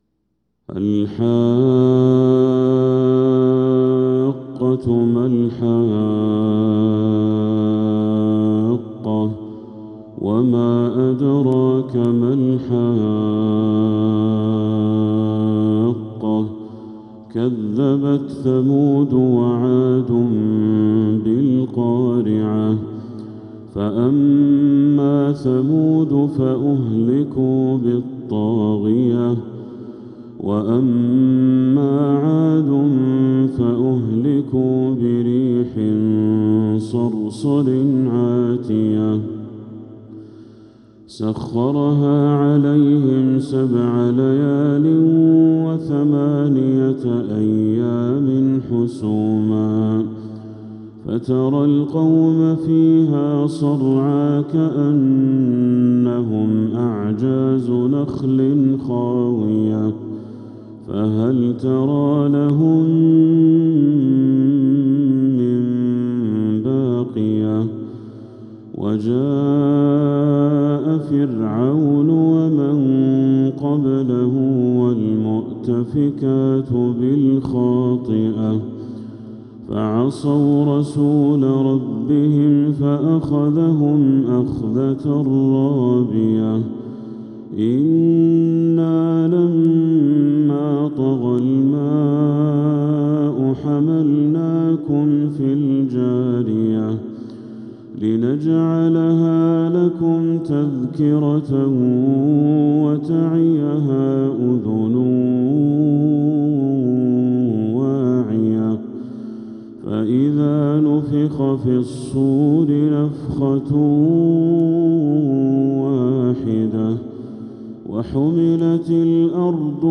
سورة الحاقة كاملة | محرم 1447هـ > السور المكتملة للشيخ بدر التركي من الحرم المكي 🕋 > السور المكتملة 🕋 > المزيد - تلاوات الحرمين